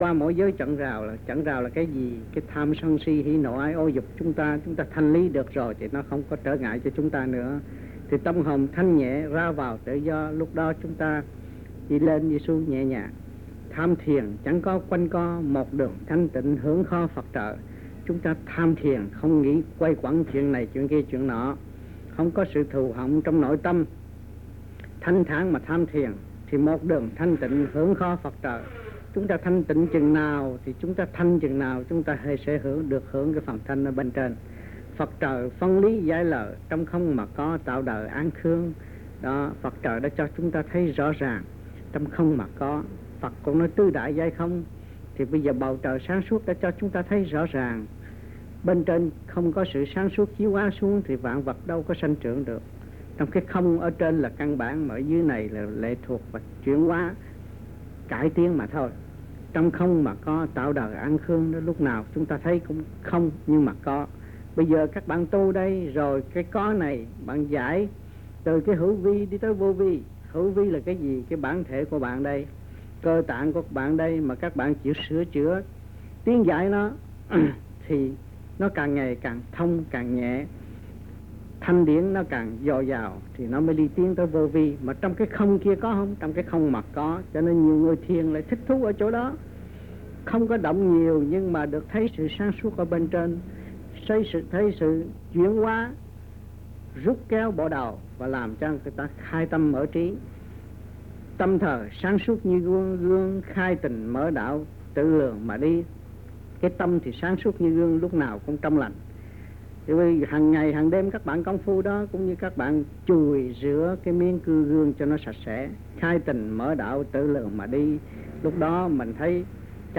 Bài Giảng Về Thiền (B)
Địa danh : Sài Gòn, Việt Nam
Trong dịp : Sinh hoạt thiền đường